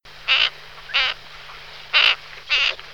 Héron gardeboeufs
Bubulcus ibis
heron_gb.mp3